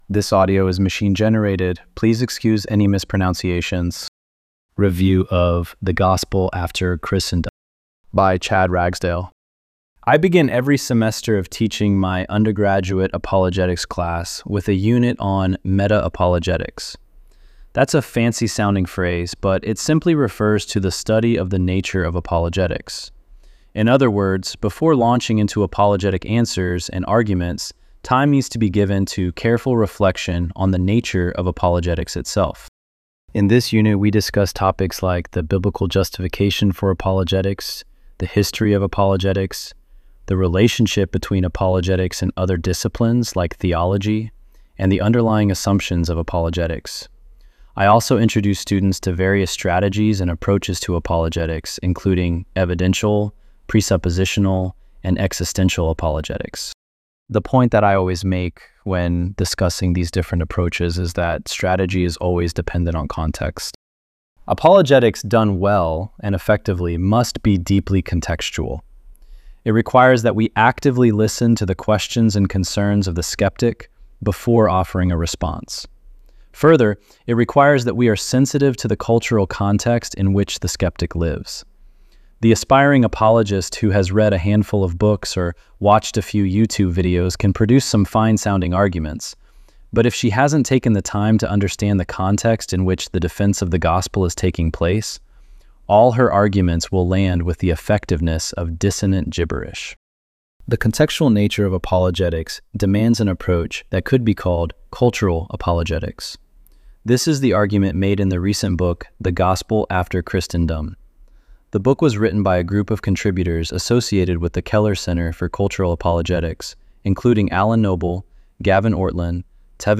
ElevenLabs_2_17.mp3